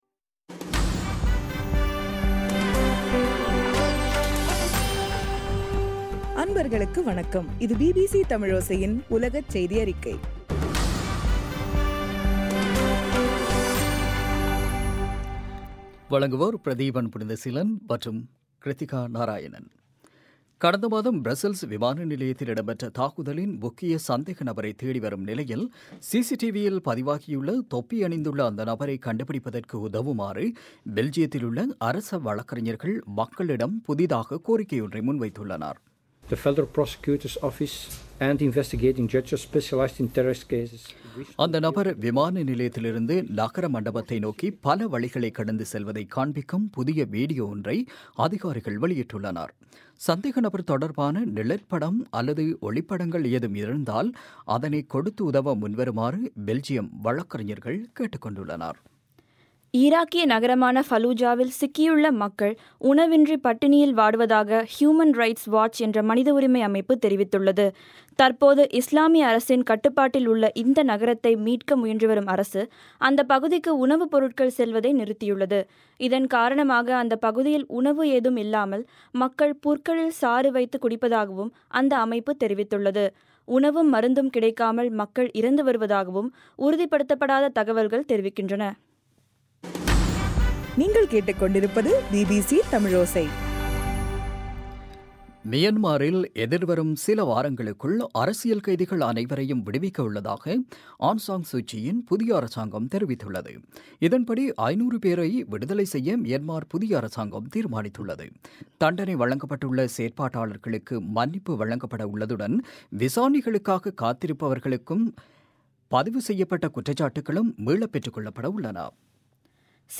ஏப்ரல் 7, 2016 பிபிசி செய்தியறிக்கை